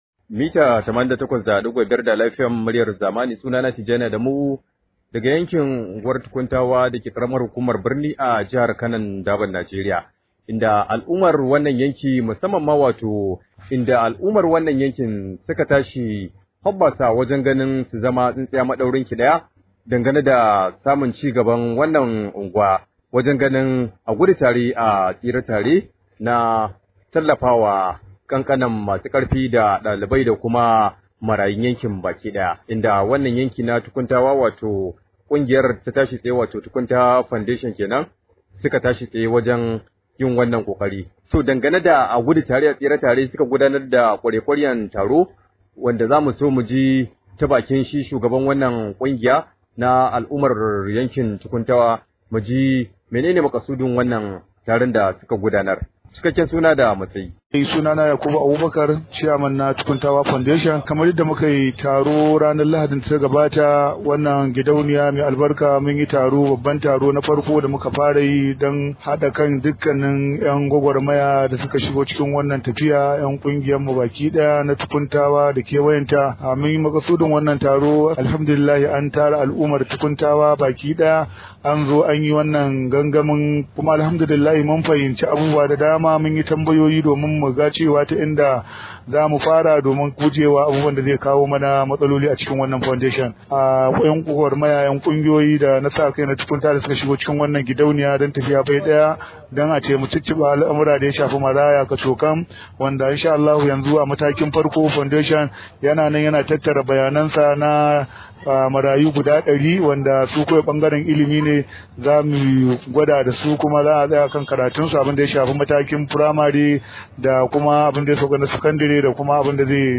Rahoto: Za mu tallafawa marayu dari a yankin mu – Tukuntawa Foundation